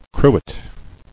[cru·et]